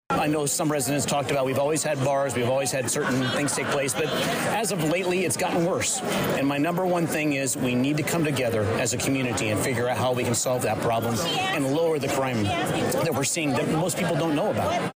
Full Crowd Comes to St. Mary’s Church for Westville Candidates Forum